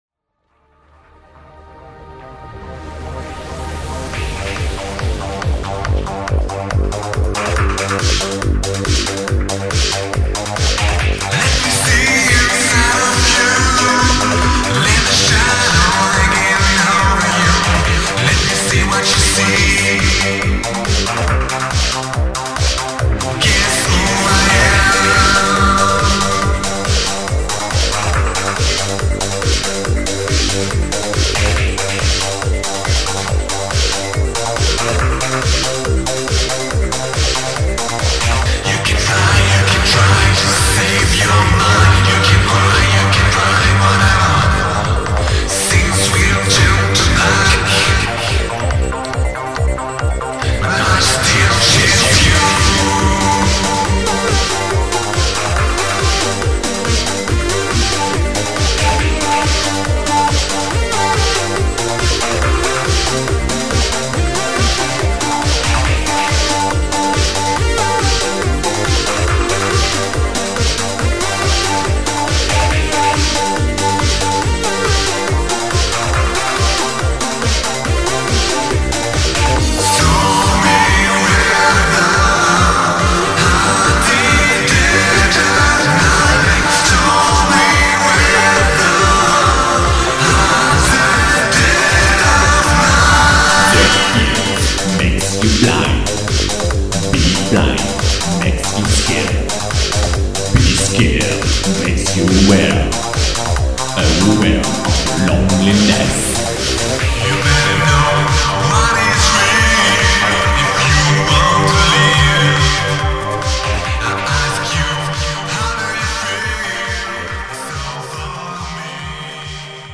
All the following songs/samples have been degraded.